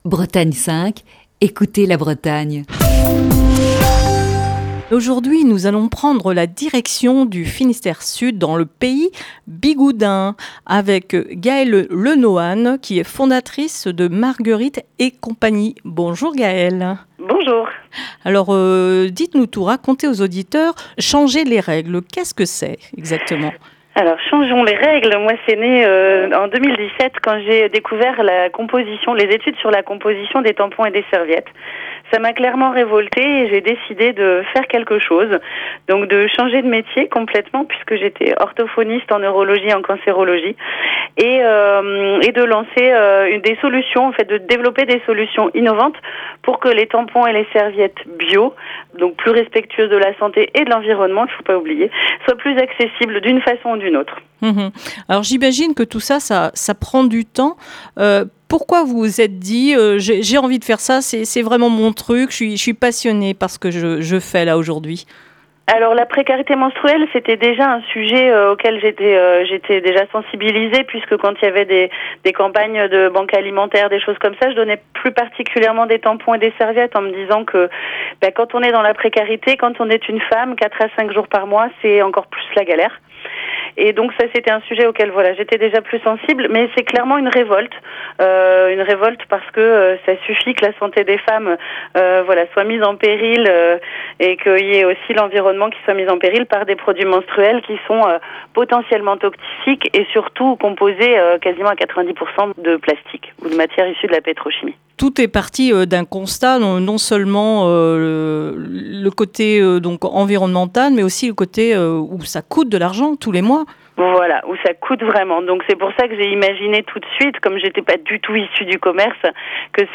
Elle est au téléphone avec